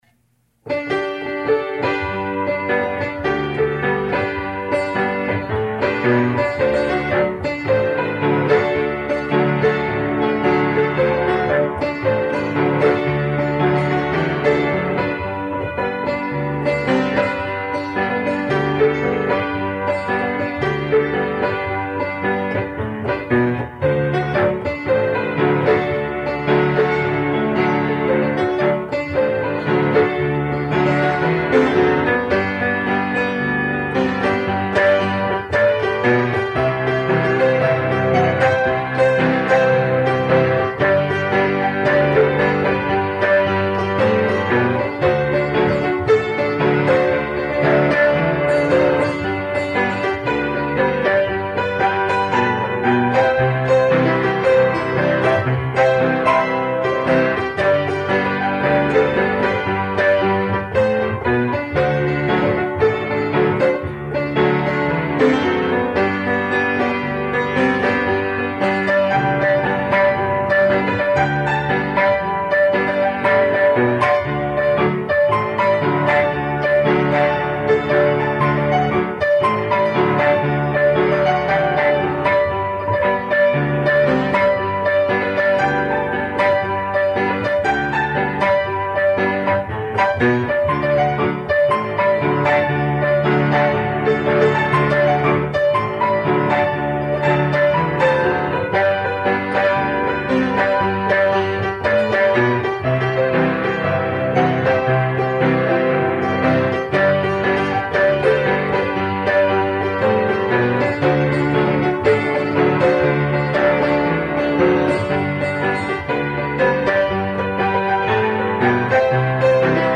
Piano Song Is Right Here
PIANO1Mod!.mp3